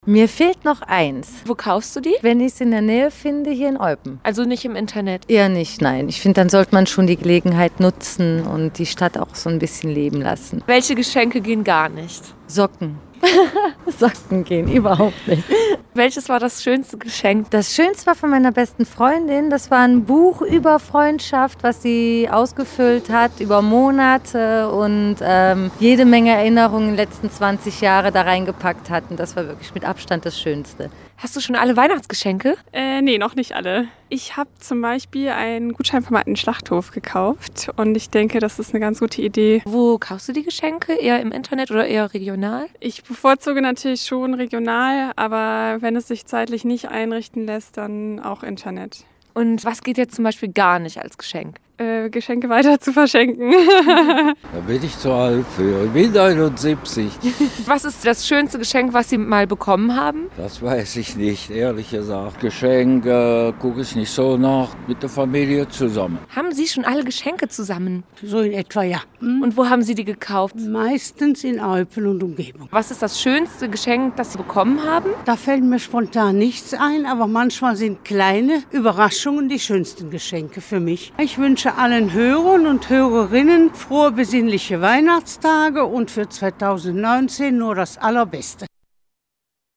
Hinweis an Eltern: Der Humor hier ist etwas deftig…